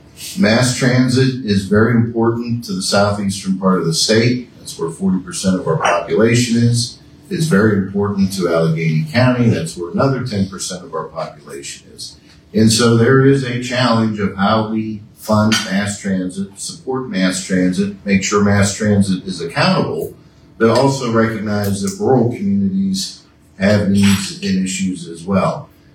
State Senator Joe Pittman and State Representative Jim Struzzi talked about the budget impasse at Eggs and Issues event hosted by the Indiana County Chamber of Commerce this morning.